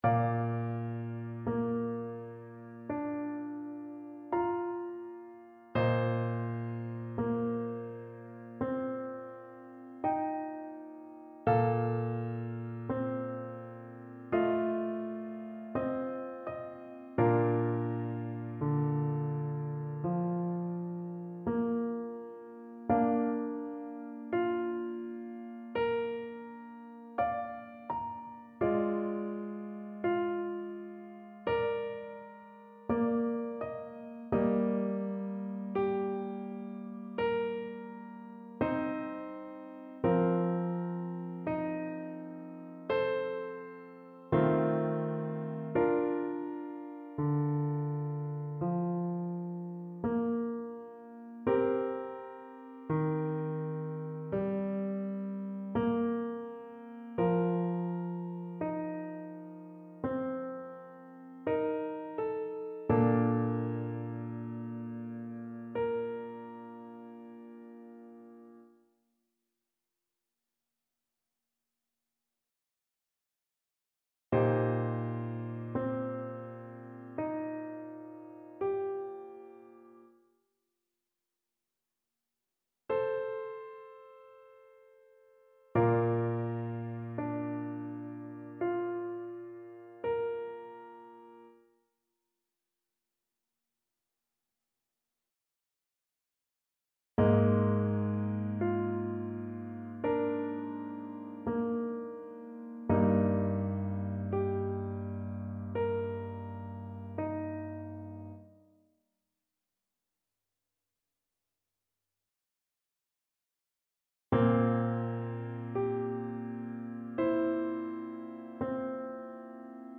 Flute version
4/4 (View more 4/4 Music)
Bb5-D7
=56 Trs lent
Classical (View more Classical Flute Music)